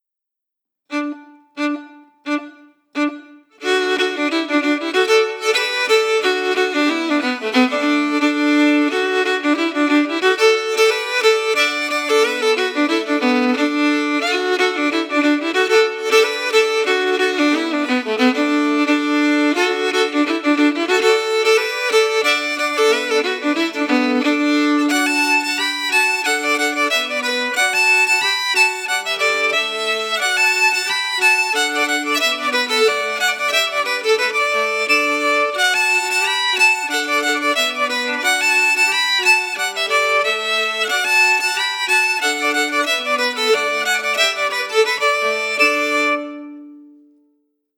Key: D
Form: Reel
MP3: (Melody emphasis, played by the composer)
Genre/Style: “Pseudo-Appalachian”, according to the composer